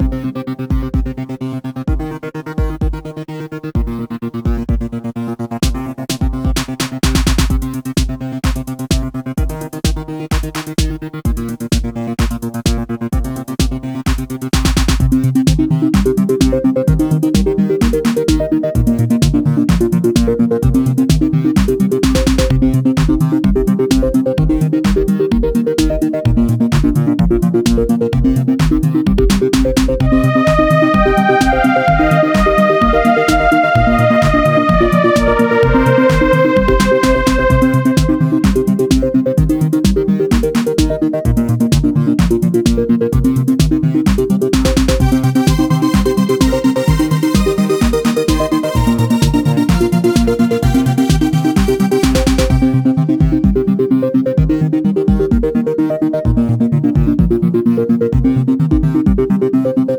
A quick, 60 second loop written for a coding challenge where I only had 4 hours to work on the project.